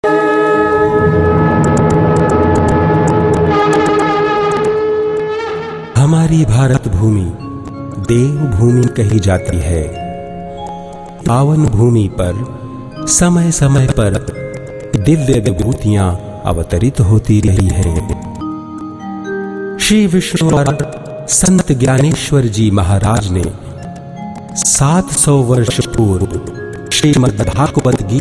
His sweet and warm, rich and distinctively English and Hindi Voice has enhanced the image of many of today’s leading brands .
indisches engl.
Sprechprobe: eLearning (Muttersprache):